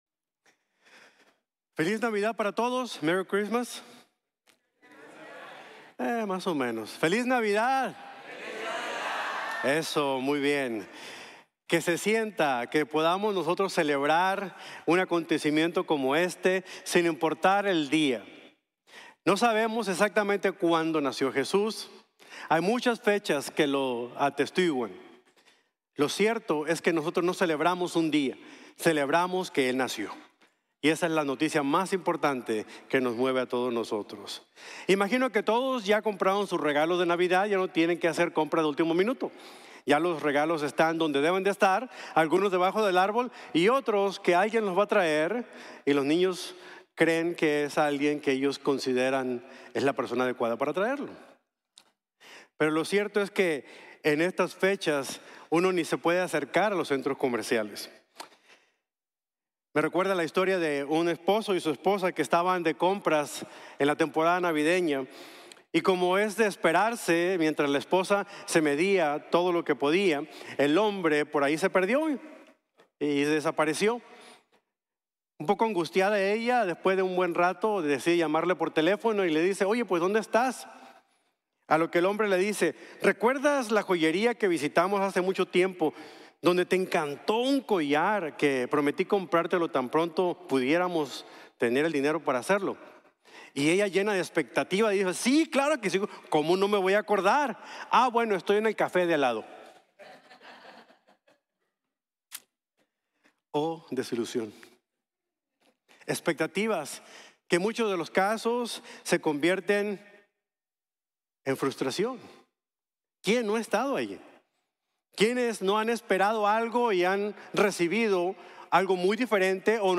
Navidad | Sermon | Grace Bible Church